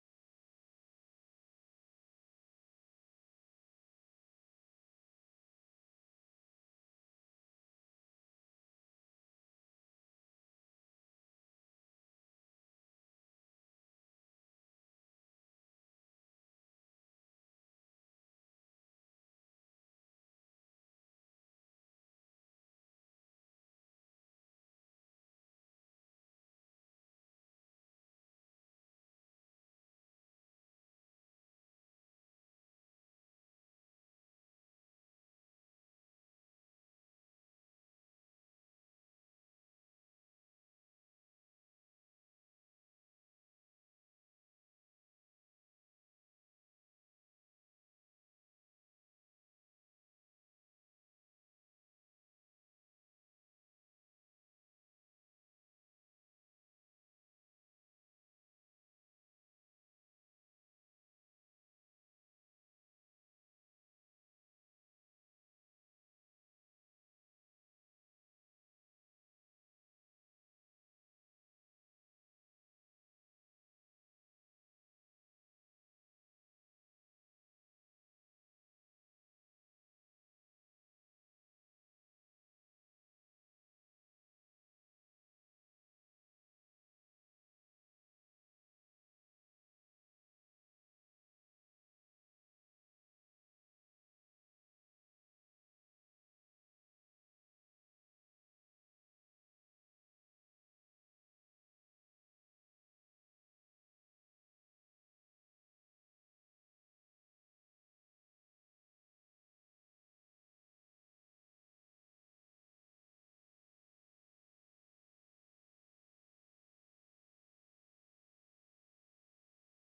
This sermon will explore how to avoid obsessing over others’ opinions and be transformed into the image of Jesus Christ.